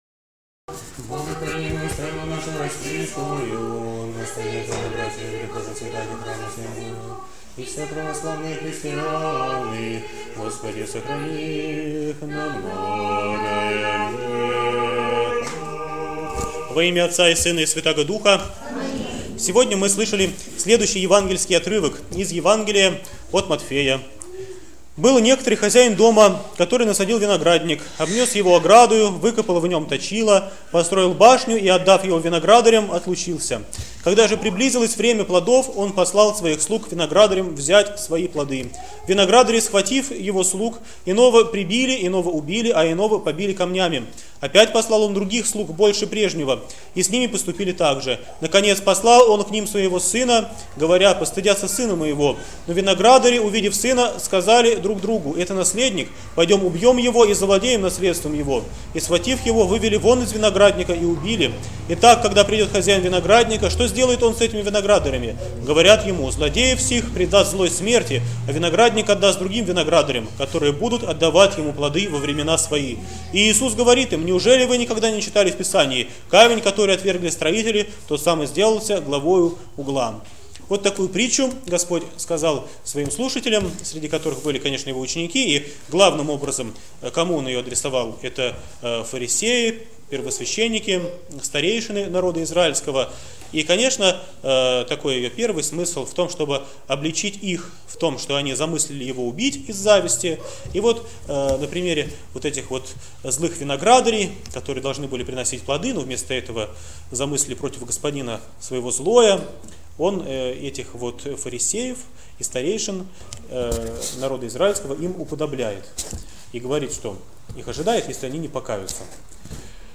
ПРОПОВЕДЬ В НЕДЕЛЮ 13-Ю ПО ПЯТИДЕСЯТНИЦЕ